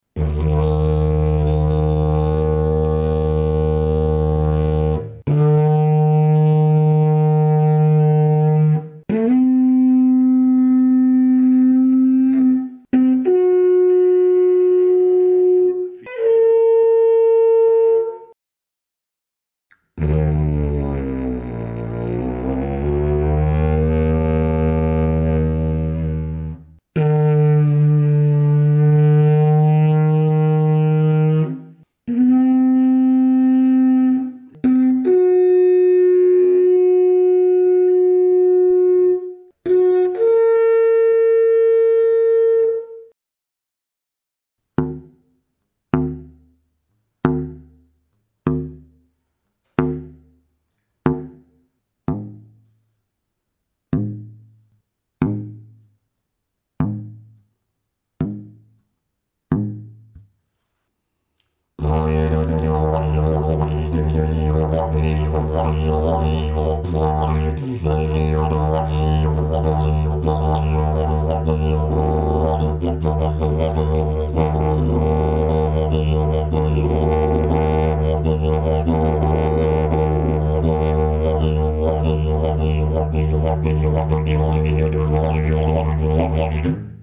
Dg553 ist ein Didgeridoo des Modells 049 gestimmt in D#2, mit dem Overblow etwas unter der Oktave bei, D#3-30.
Die Form erzeugt einen ruhigeren nicht so stark dröhnenden Grundton, der sich gut mit Stimmeffekten modulieren lässt und dadurch auch Mikrophone nicht leicht übersteuert.
The fundamental tone has excellent resonance.
This shape produces a calmer, less boomy fundamental tone that modulates well with vocal effects and is therefore less prone to microphone distortion.
Dg553 Technical sound sample 01